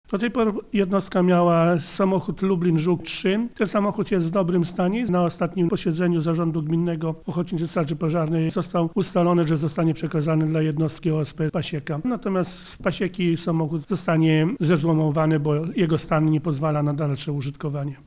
Burmistrz Witold Kowalik podkreśla, że zakup nowego samochodu to dobra wiadomość także dla jednostki z Pasieki.